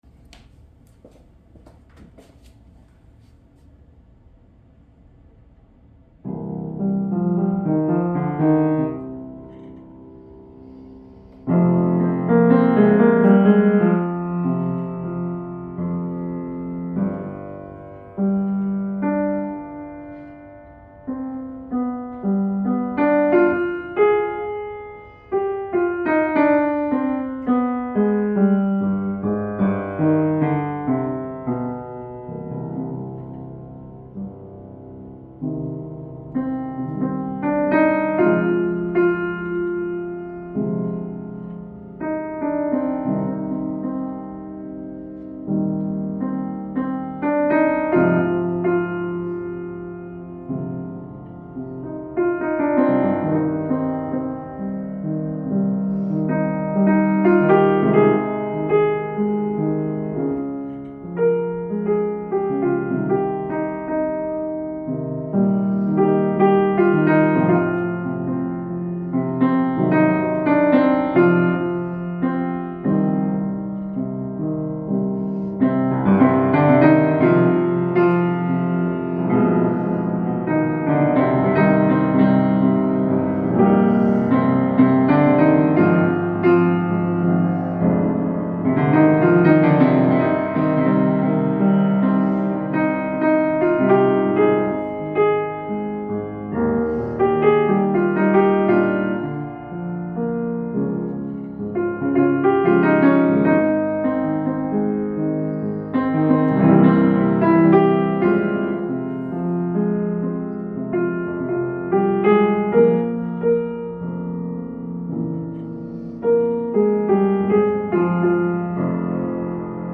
piano transcription